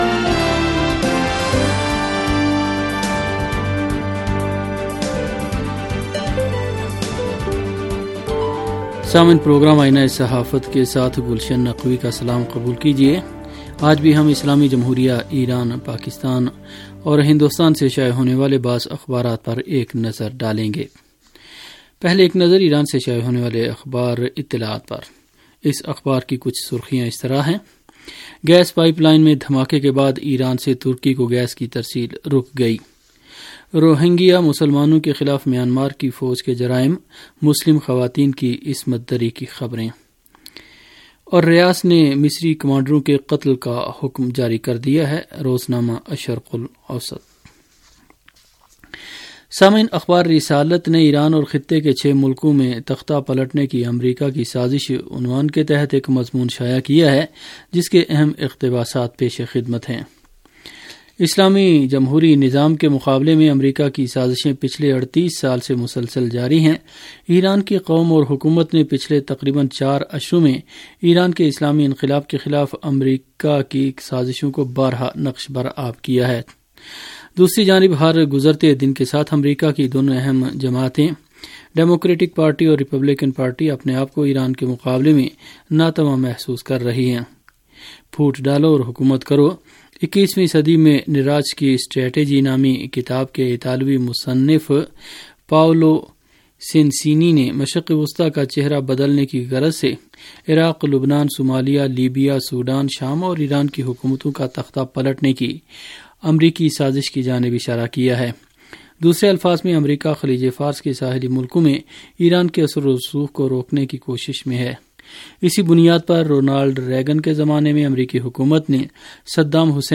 ریڈیو تہران کا اخبارات کے جائزے پر مبنی پروگرام